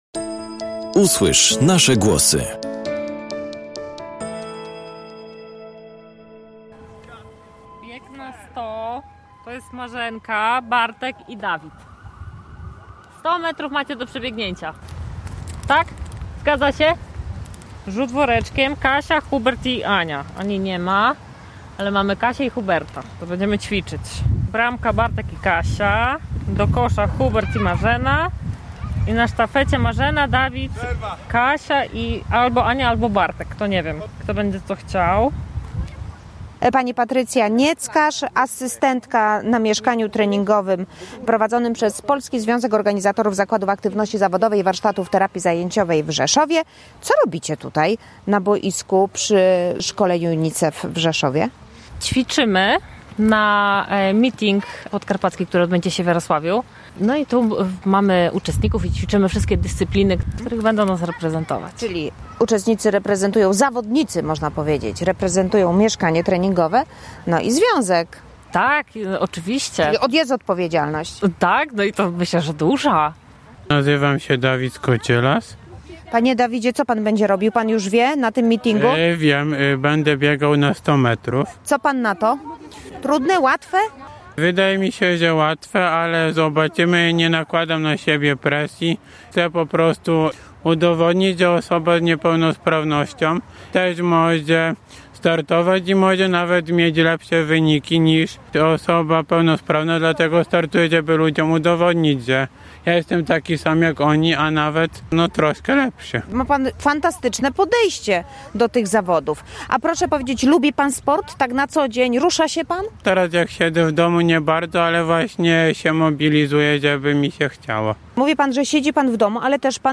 rozmawiała o zawodnikami z Rzeszowa o emocjach sportowych